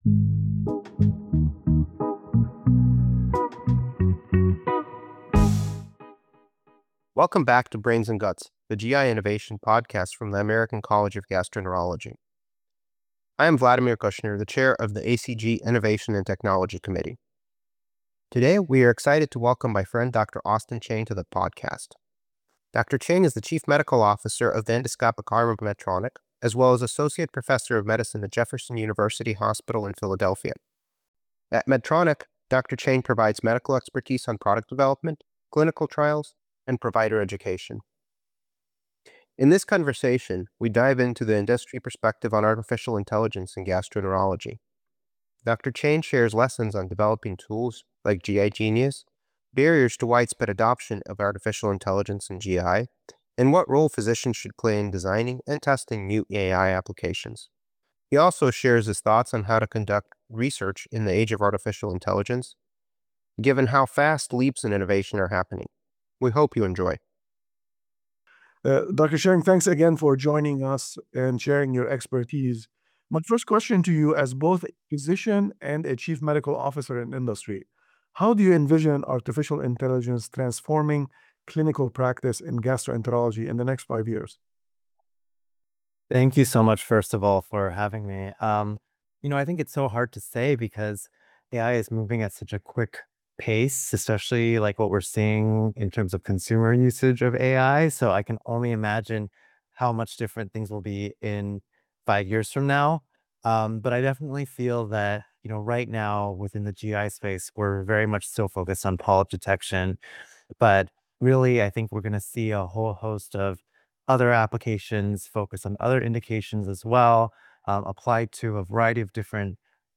In each episode of Brains & Guts, we interview gastroenterology innovators and inventors to unpack their successes, pitfalls, and learnings.